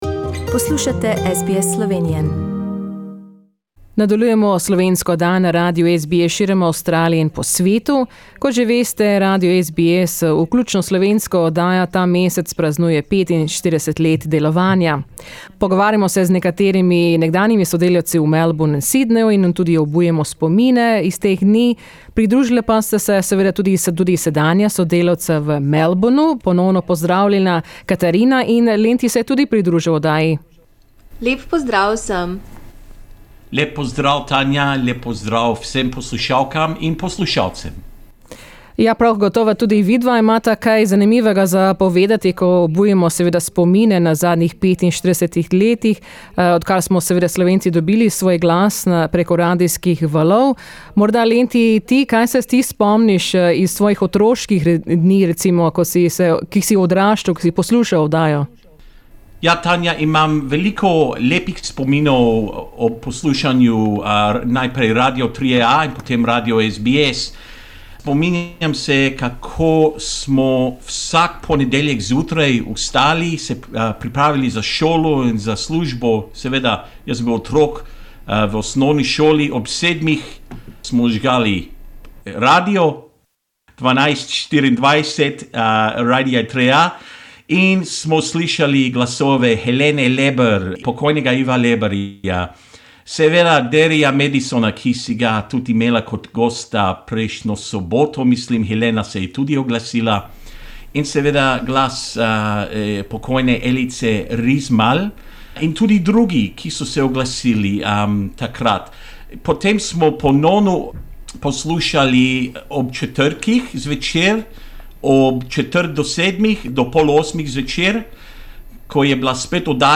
Kako pa na radijskih valovih plavajo trenutni ustvarjalci? Prisluhnite klepetu, ki je nastal na daljavo.